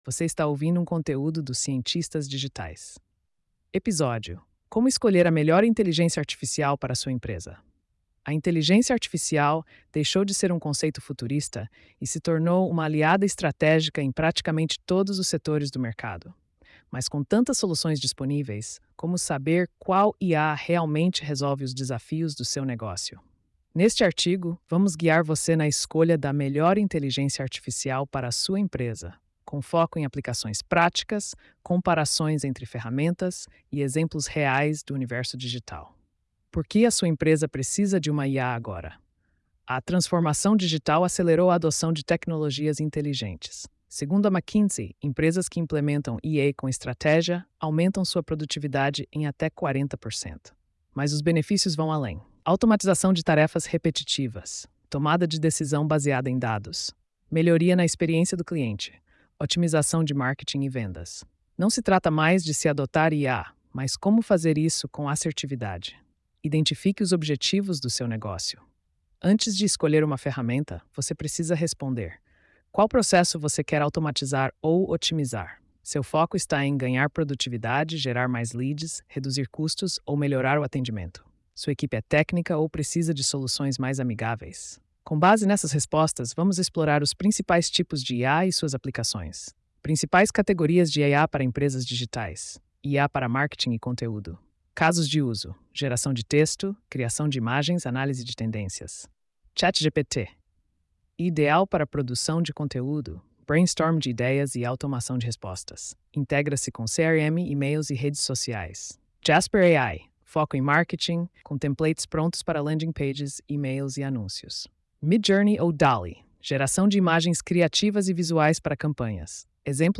post-3127-tts.mp3